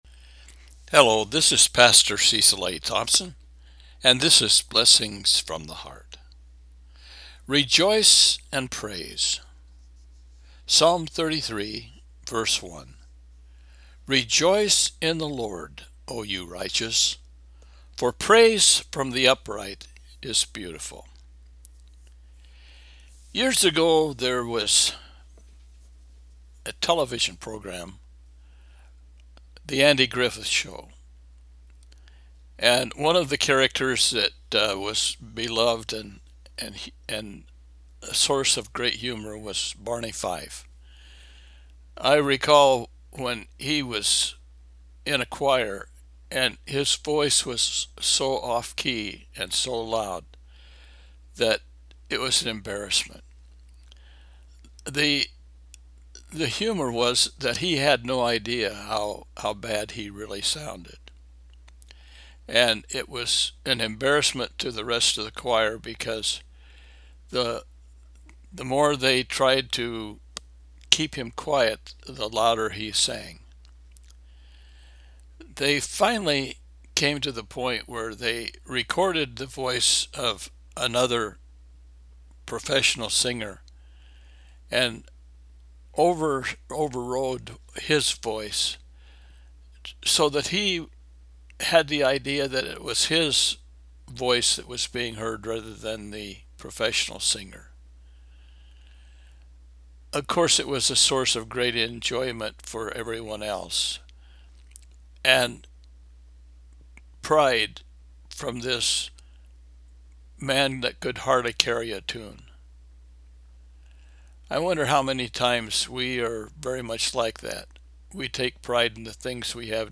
Psalm 33:1 – Devotional